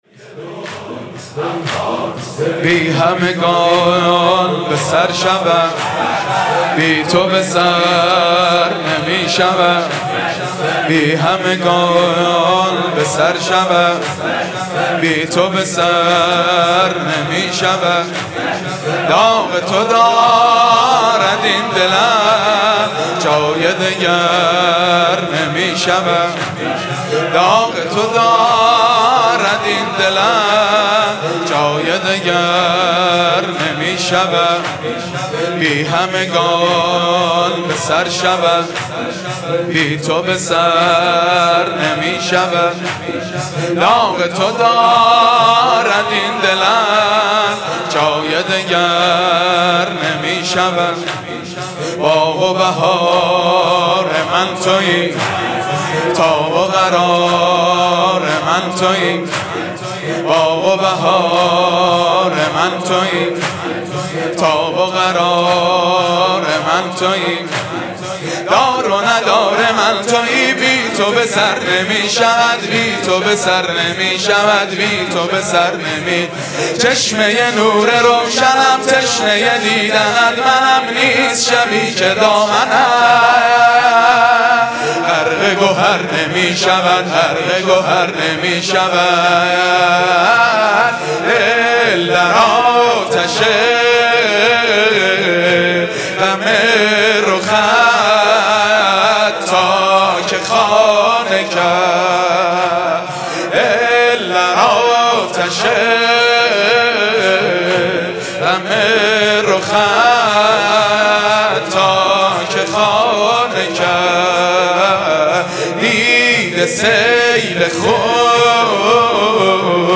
مناسبت : شب پنجم محرم
مداح : محمود کریمی قالب : زمینه